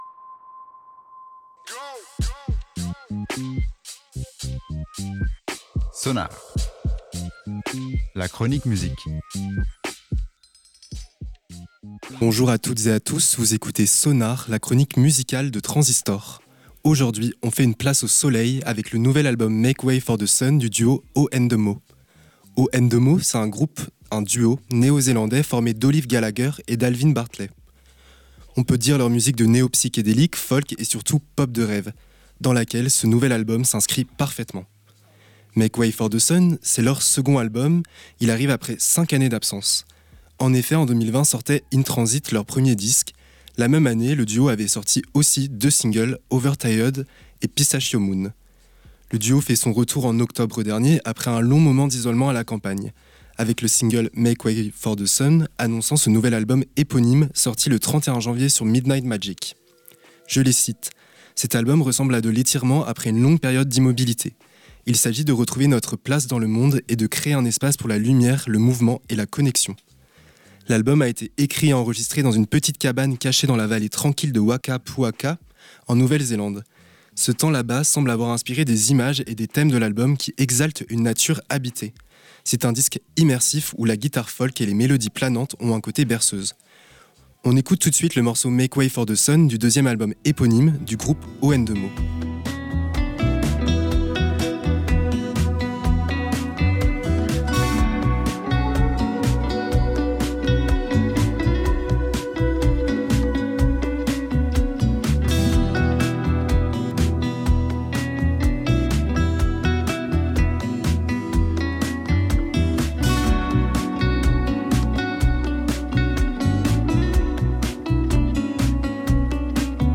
Les morceaux sont très planants, tranquilles.